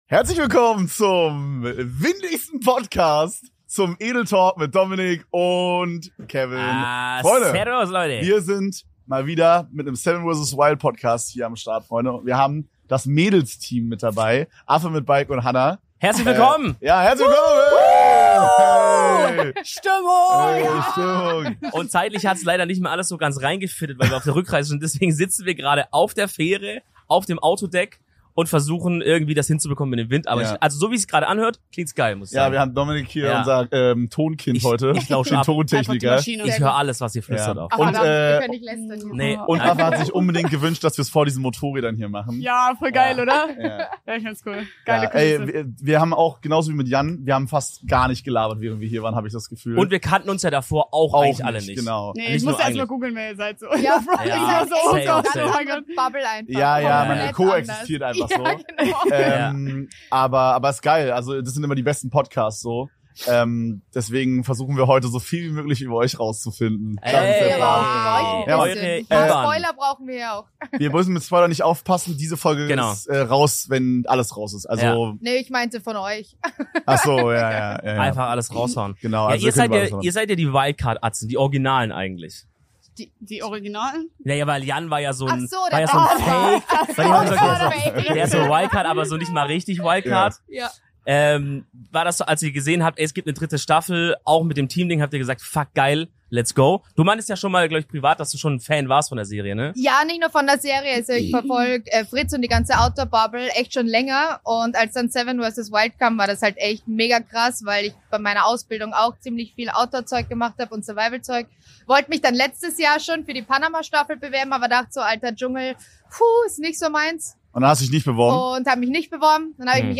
In der wohl windigsten Edeltalk Folge jemals